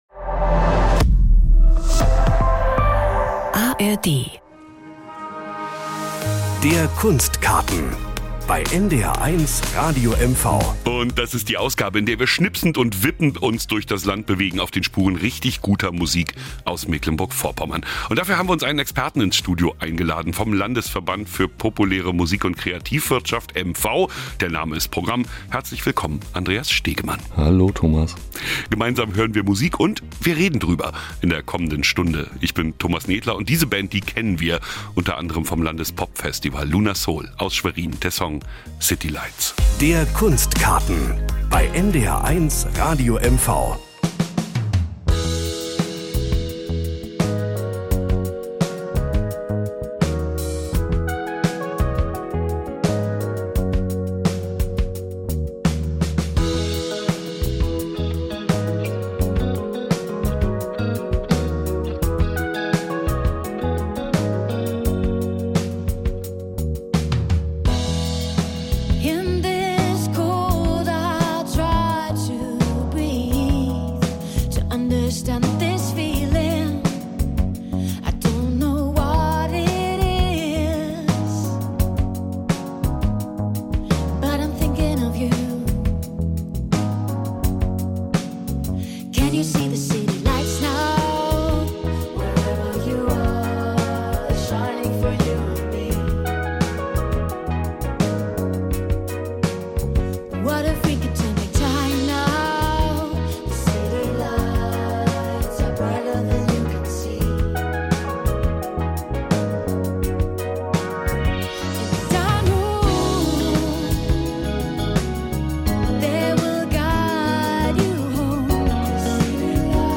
Was braucht die Musikszene, was brauchen die Veranstalter und Festivals im Land? Starke Themen - ein gutes Gespräch und dazu viel Musik aus unserem Land.